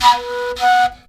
FLUTELIN01.wav